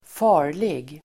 Uttal: [²f'a:r_lig]